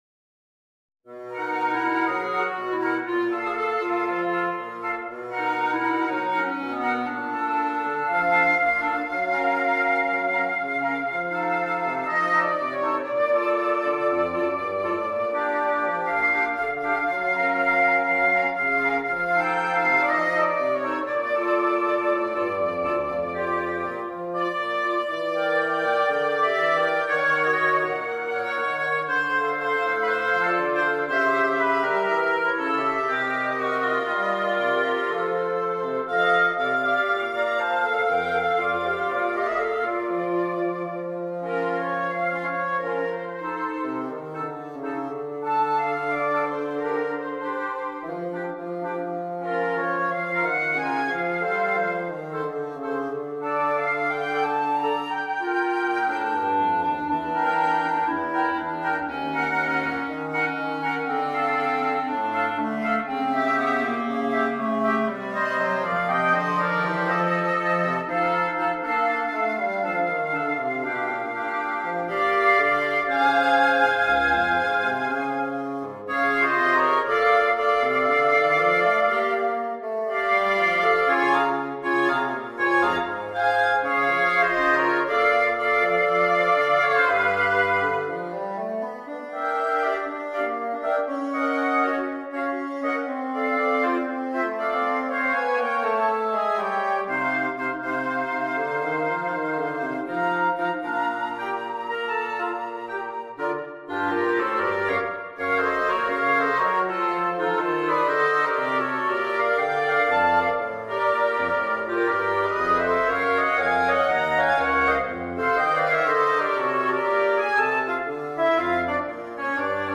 for Woodwind Quartet
arranged for Woodwind Quartet